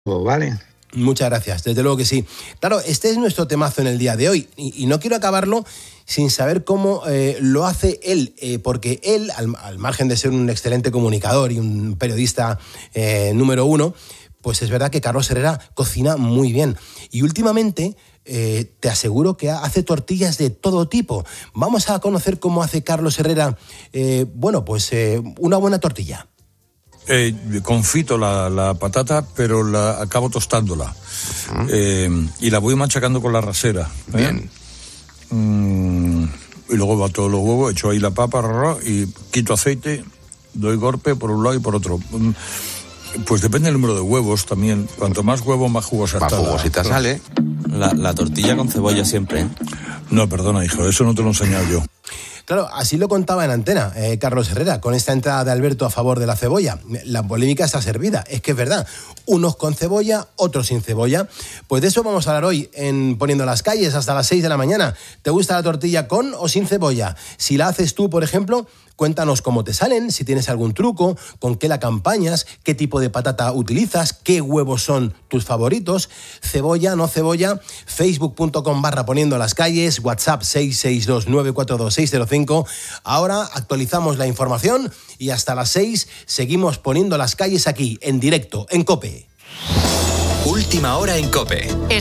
El comunicador desvela en 'Poniendo las Calles' su método para lograr la tortilla perfecta y reaviva el eterno debate sobre si debe llevar cebolla o no